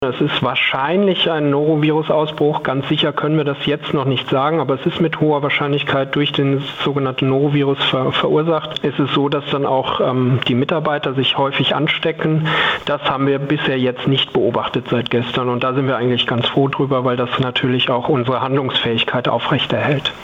Noro-Virus Ausbruch am Leopoldina Krankenhaus. Interview mit - PRIMATON